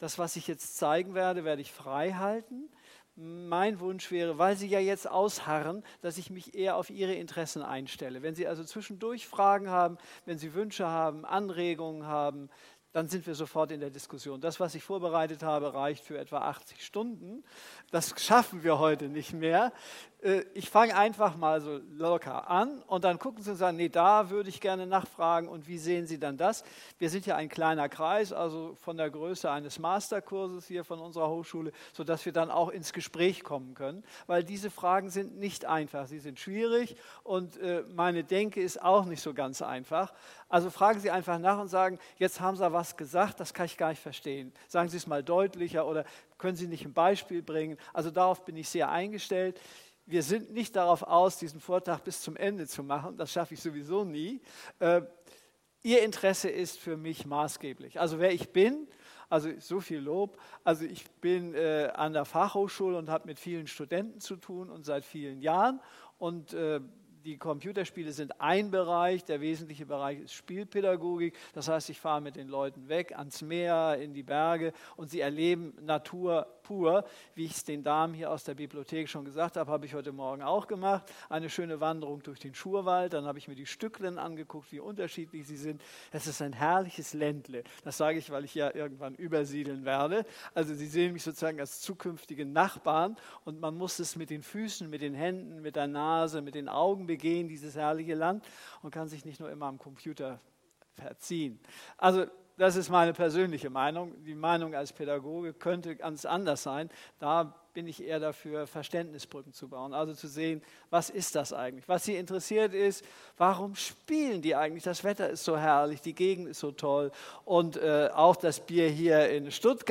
Der Vortrag als MP3 > Spiele bauen beim Spielen einen MagicCircle auf. Dieser MagicCircle umgibt das Spiel und schottet es gegen äussere und innere Einflüsse ab.